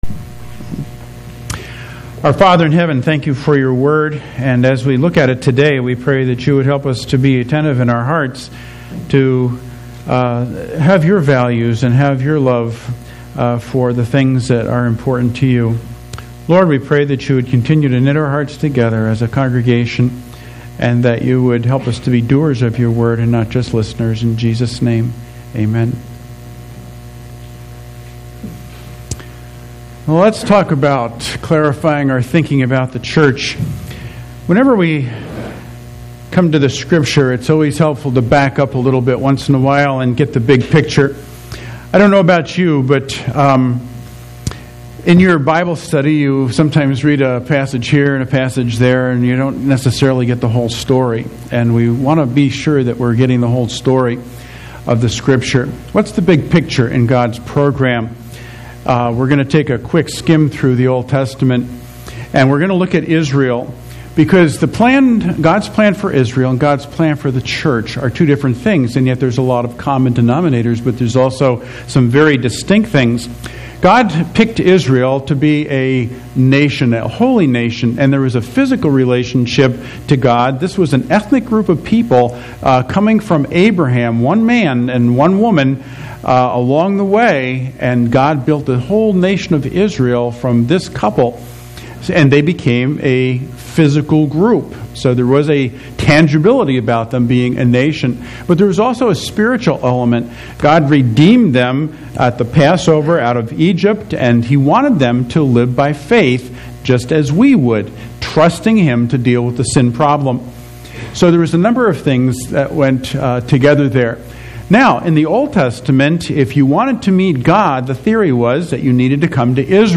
Walk through the book of Acts with this sermon and understand the beginning of the church and what God had in mind when He put it together.